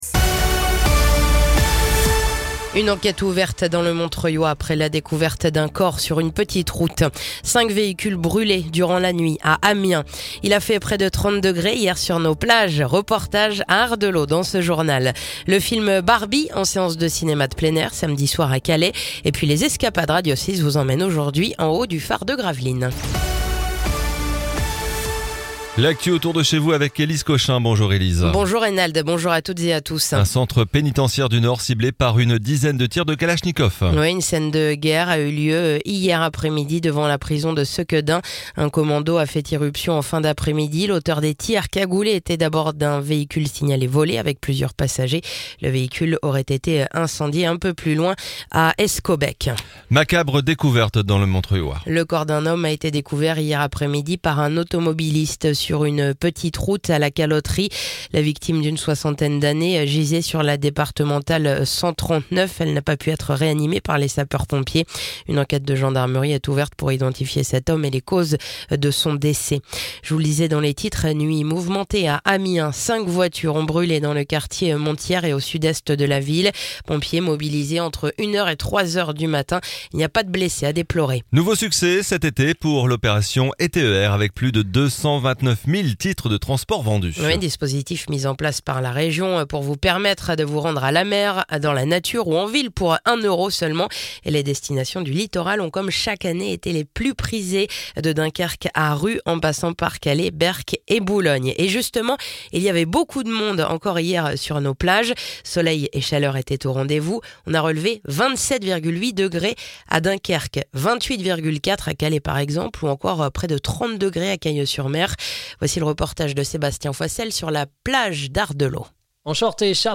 (journal de 9h)